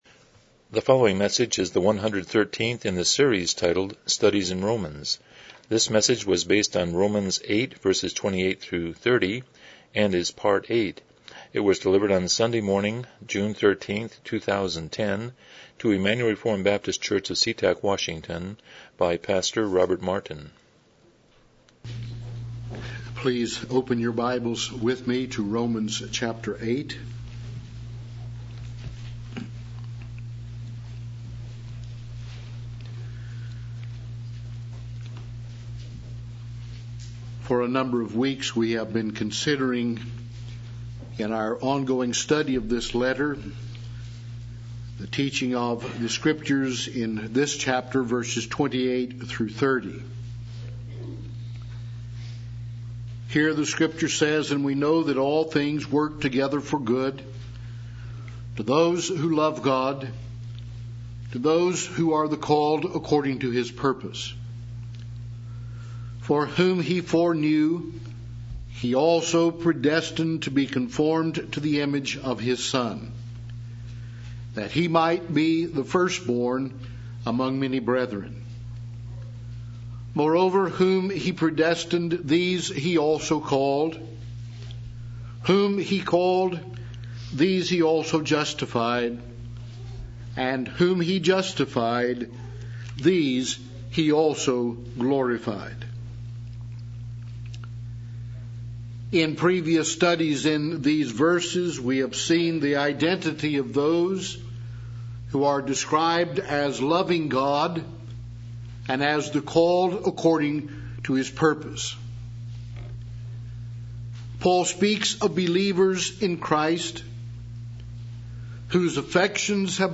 Romans 8:28-30 Service Type: Morning Worship « 99 Chapter 19.2